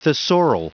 Prononciation du mot thesaural en anglais (fichier audio)
Prononciation du mot : thesaural